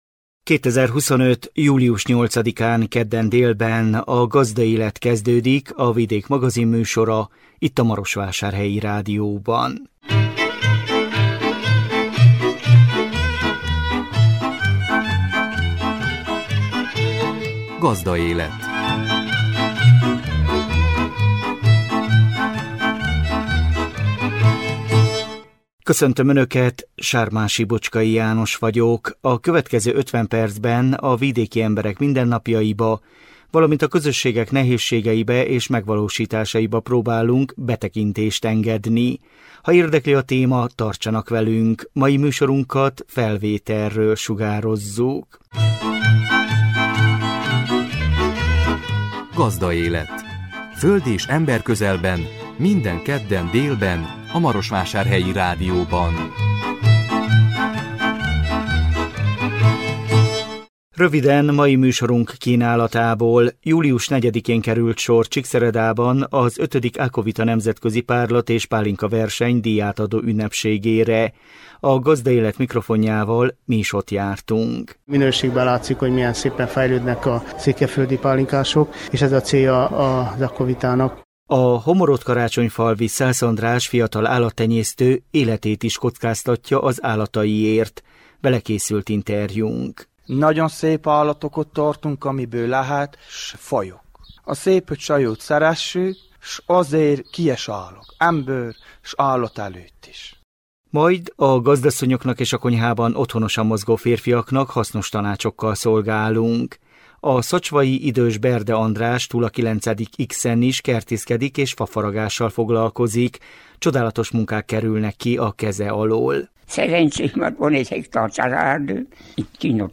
A 2025 július 8-án jelentkező műsor tartalma: Július 4-én került sor Csíkszeredában az V. Ákovita Nemzetközi Párlat- és Pálinkaverseny díjátadó ünnepségére. A Gazdaélet mikrofonjával mi is ott jártunk.
Benedek László polgármester avat be a részletekbe.